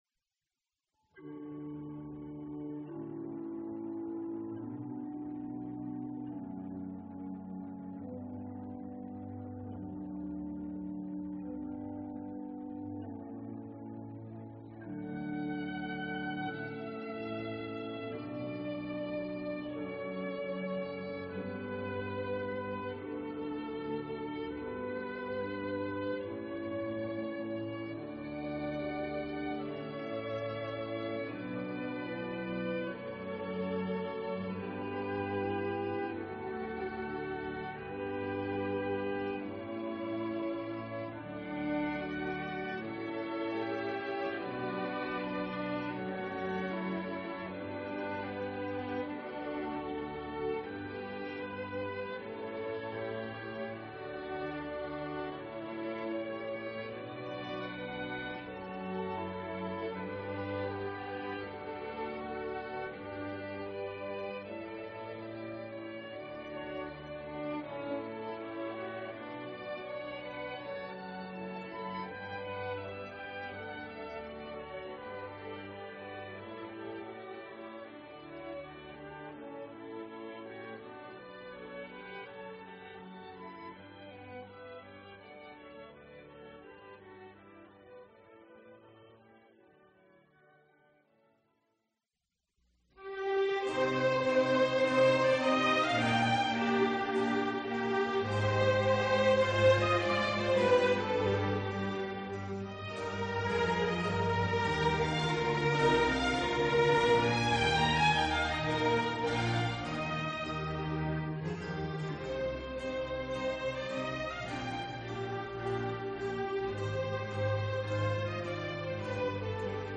all the best Baroque
Violin Concerto in C Minor, Adante
Harpsichord & Flute Concerto in D Minor, Allegro
Concerto in D for Trumpet & 2 Violins